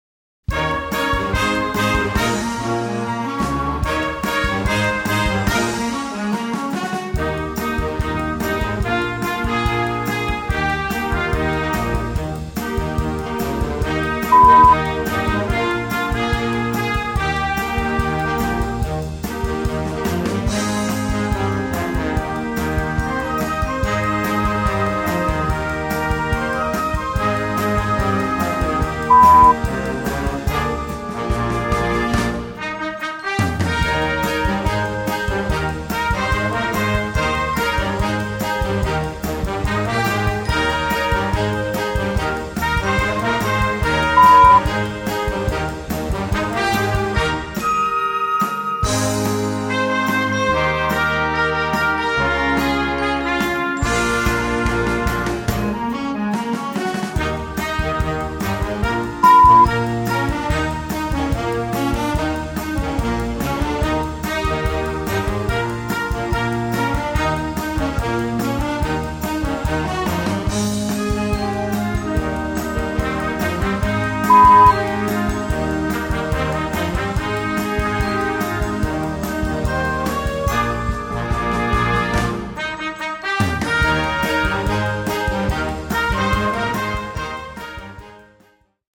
Orchestre D'Harmonie